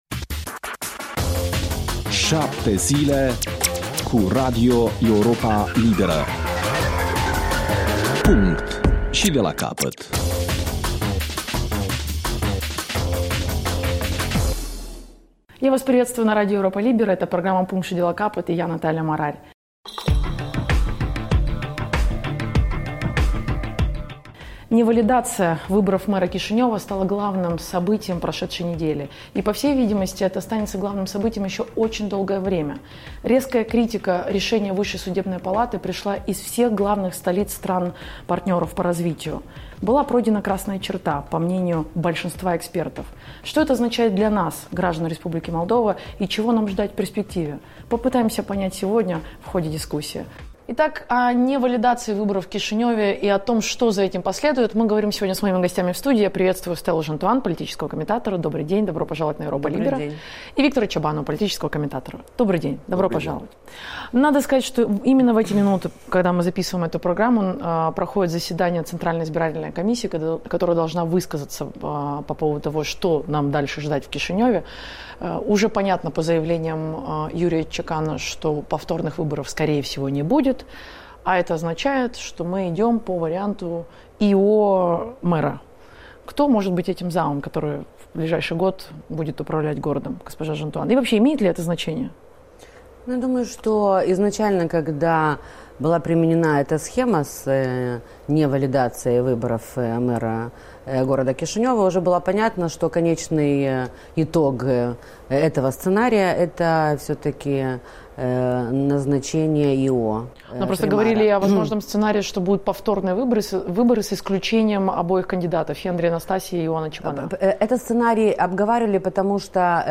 în dialog cu cu comentatorii